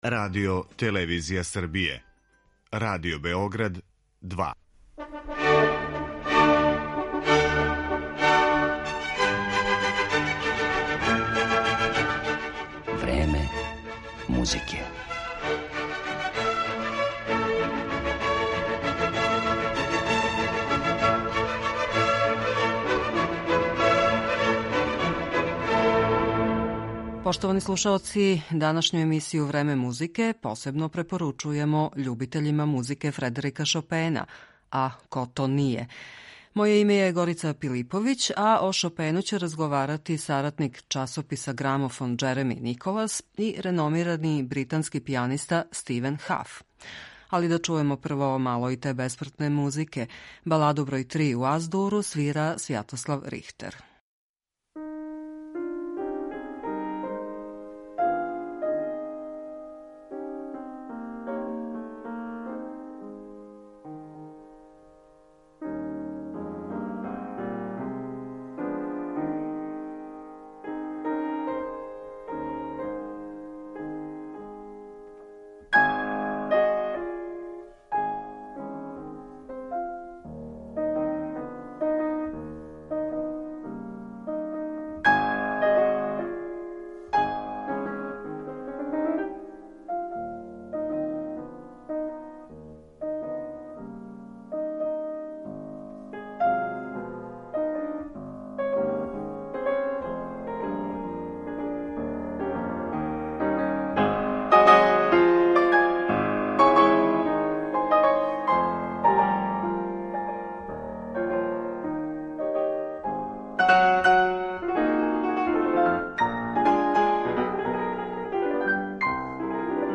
Разговор о Шопену